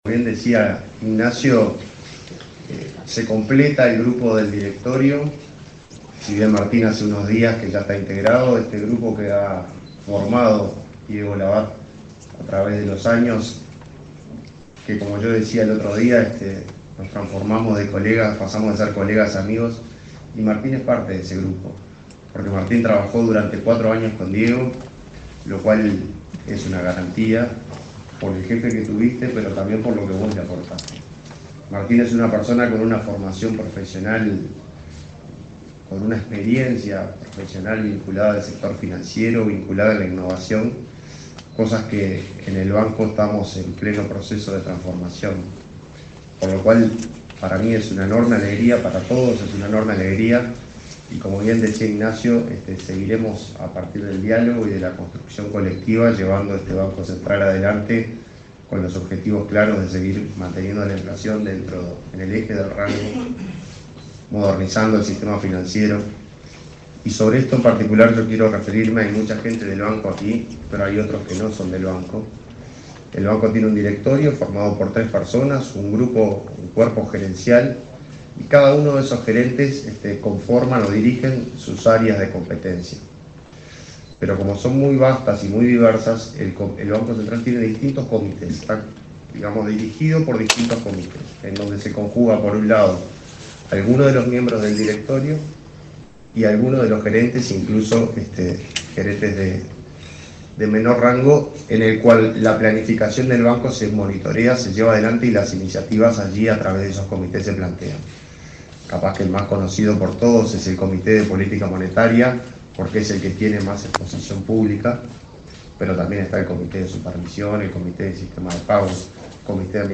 El presidente del Banco Central del Uruguay (BCU), Washington Ribeiro y el nuevo vicepresidente, Martín Inthamoussu, hicieron uso de la palabra
Palabra de autoridades del BCU 27/08/2024 Compartir Facebook X Copiar enlace WhatsApp LinkedIn El presidente del Banco Central del Uruguay (BCU), Washington Ribeiro y el nuevo vicepresidente, Martín Inthamoussu, hicieron uso de la palabra durante el acto de asunción de este último, como integrante del directorio del organismo.